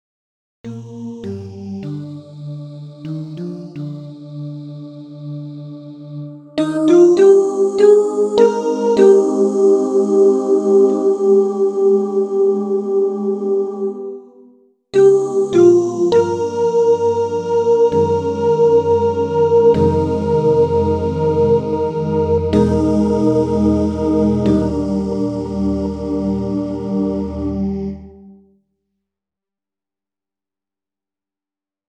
Key written in: E♭ Major
Other part 1: